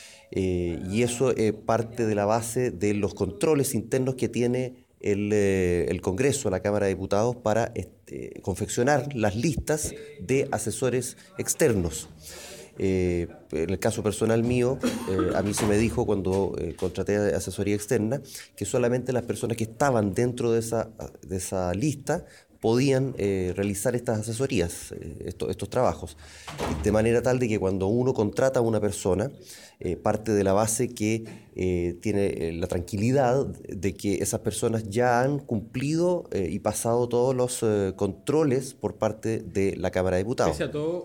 Sumando gravedad a las irregularidades existentes en las asesorías externas que se efectúan a los legisladores chilenos, el diputado Gaspar Rivas dijo a Radio Bío Bío que los legisladores están limitados por el Congreso a la hora de elegir por quién asesorarse.